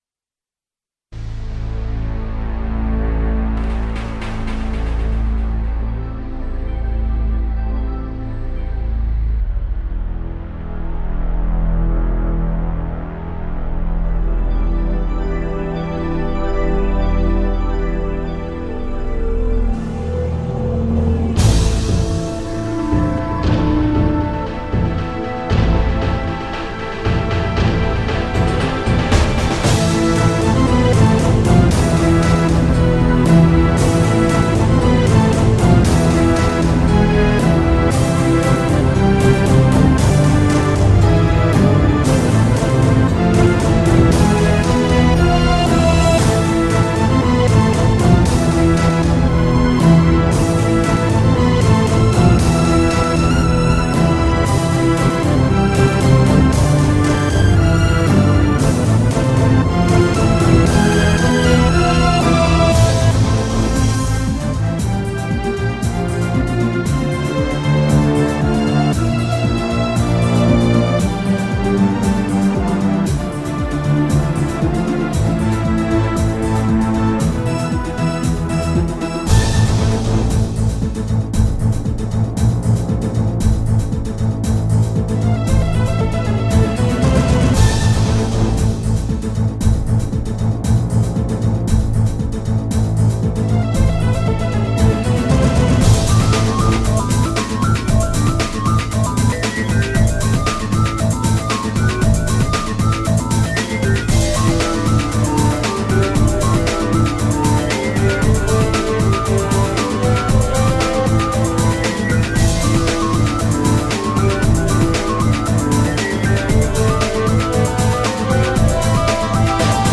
Diesmal ein ernsteres Stück, passend zum Sendernamen.